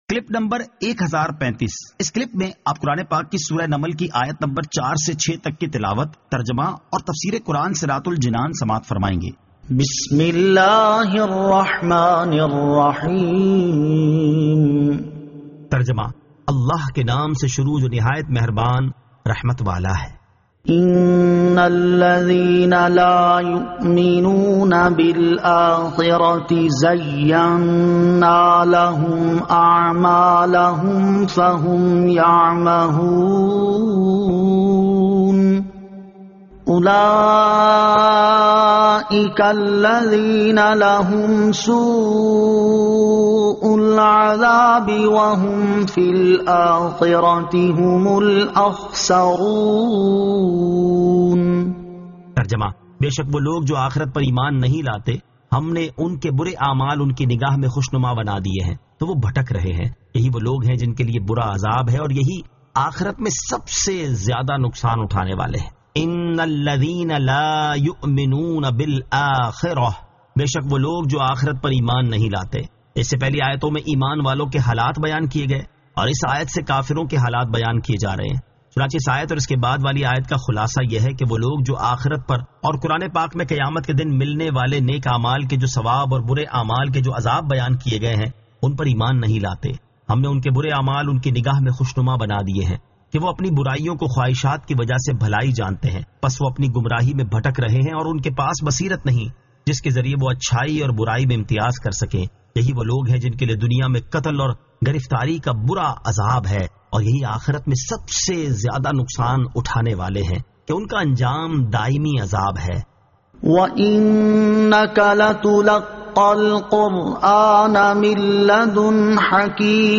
Surah An-Naml 04 To 06 Tilawat , Tarjama , Tafseer